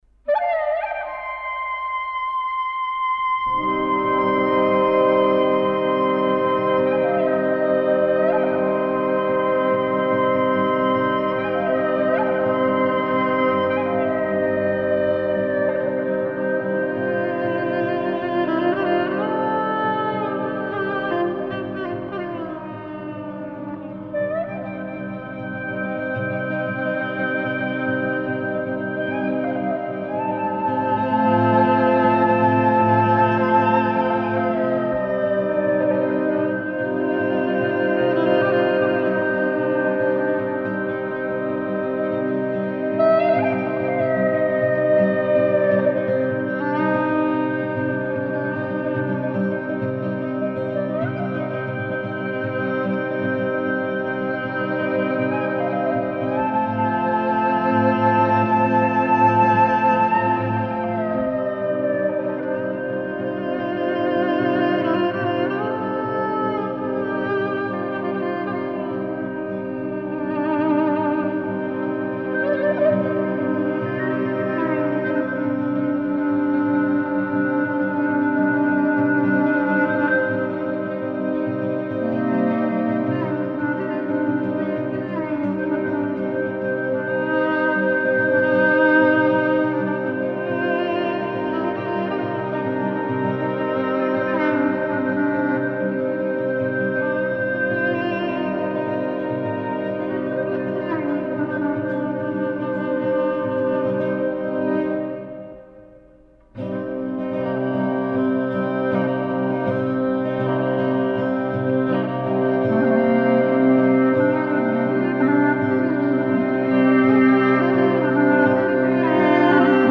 Kaba dypjesëshe: kaba dhe valle.
Pjesa e parë mbështetet mbi një motiv vajtimor tosk të zonës së Korҫës.
Tipike për këtë kaba është përdorimi i befasishëm i regjistrit të lartë të gërnetës qysh në hyrje të saj.
Kalimi në pjesën e dytë, valle, është i qetë dhe ritmi shoqërues i llautës nuk prish dialogët e instrumenteve të cilat tashmë ndodhin në kontekstin e valles.